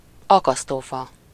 Ääntäminen
IPA: /ɣɑlx/